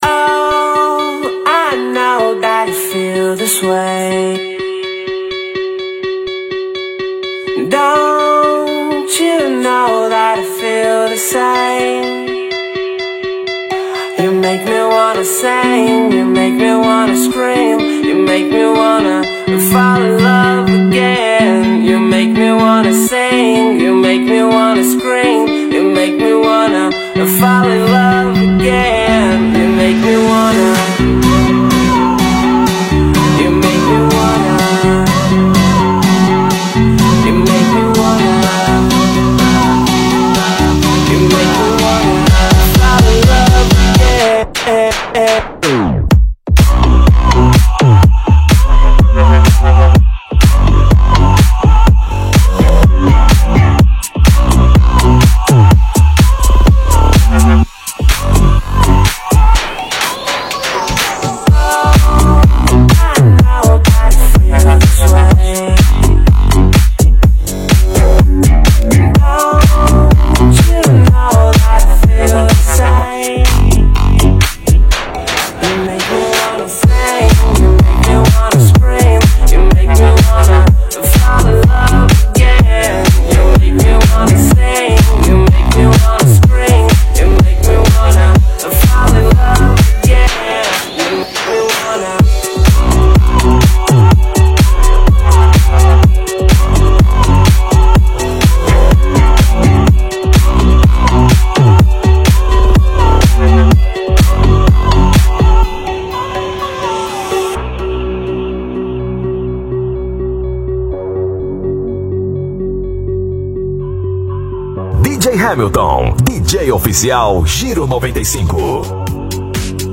Brazilian Bass